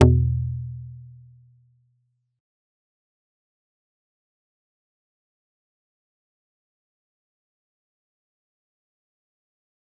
G_Kalimba-E2-mf.wav